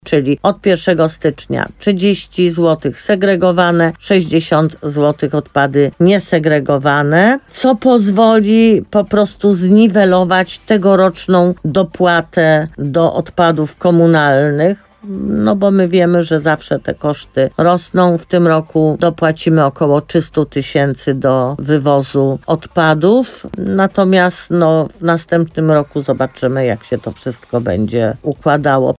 – Chcąc chronić się przed uchyleniem uchwały wprowadziliśmy teraz do niej konkretne kwoty – powiedziała w programie Słowo za Słowo w radiu RDN Nowy Sącz burmistrz Limanowej Jolanta Juszkiewicz.
Rozmowa z Jolantą Juszkiewicz: Tagi: Słowo za Słowo Limanowa Regionalna Izba Obrachunkowa Jolanta Juszkiewicz podwyżka za śmieci wywóz odpadów